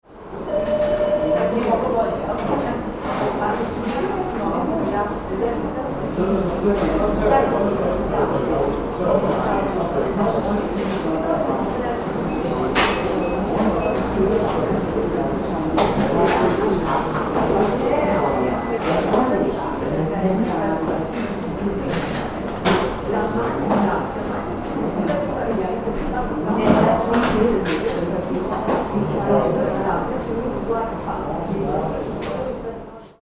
Bank-lobby-ambient-noise-realistic-sound-effect.mp3